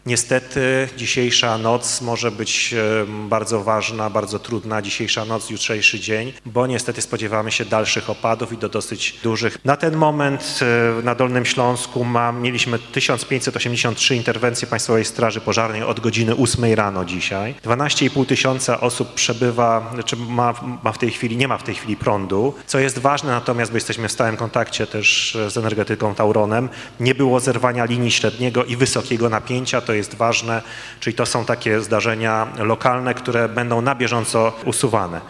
Wojewoda przedstawił jak rysowała się sytuacja w sobotę, do godz. 20:30 w liczbach.